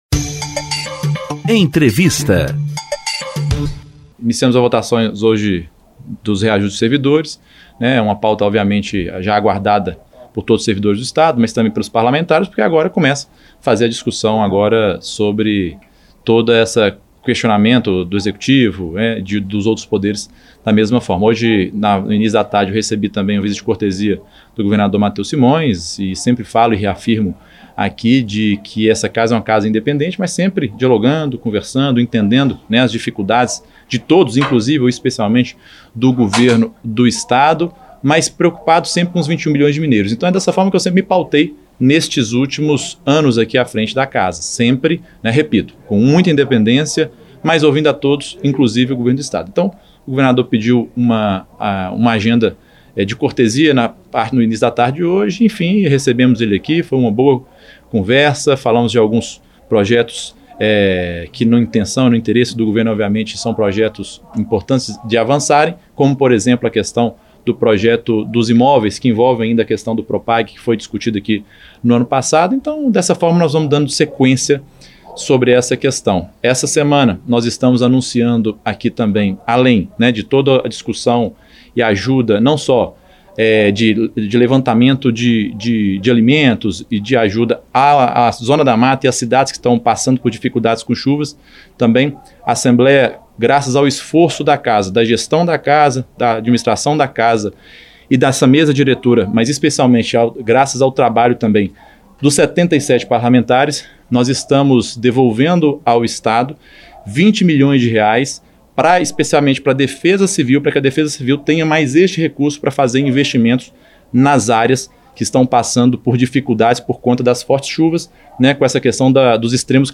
Entrevistas
Na entrevista coletiva, o presidente da ALMG também comentou a aprovação, em 1º turno, dos projetos de lei que tratam da revisão salarial dos servidores públicos e da visita do governador Mateus Simões (PSD) ao Legislativo.